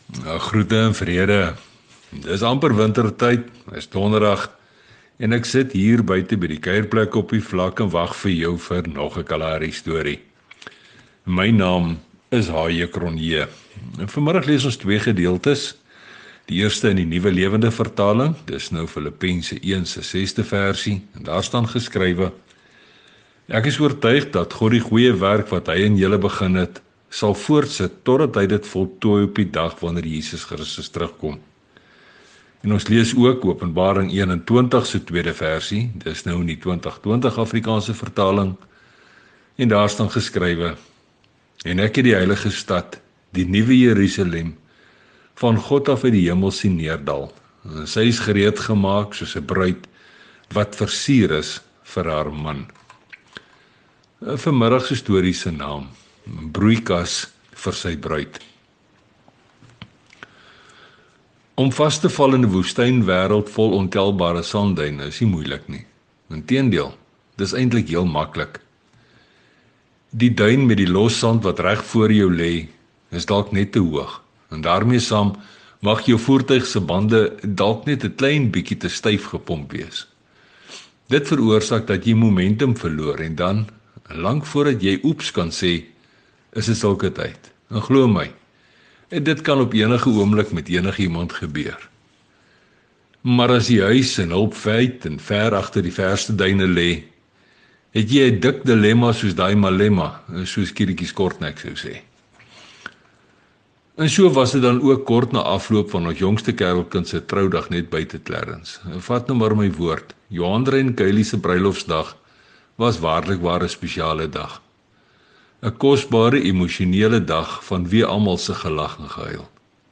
Sy getuienis verhale het 'n geestelike boodskap, maar word vertel in daardie unieke styl wat mens slegs daar op die kaal vlaktes kan optel.